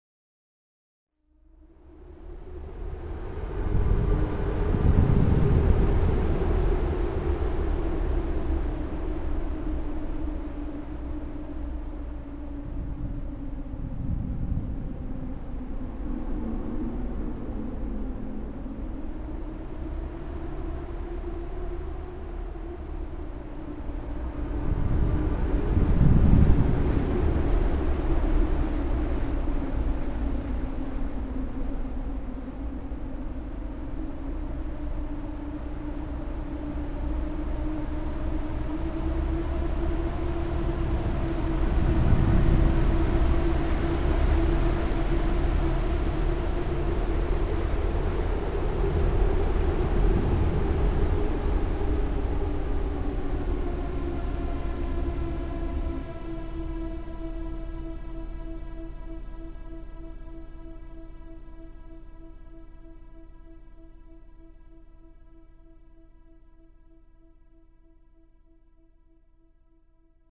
3D spatial surround sound "Blizzard, winter"
3D Spatial Sounds